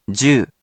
We have our computer friend, QUIZBO™, here to read each of the hiragana aloud to you.
#1.) Which hiragana youon do you hear? Hint: 【jyu】
In romaji, 「じゅ」 is transliterated as 「jyu」which sounds sort of like the 「ju」 in 「juice」 .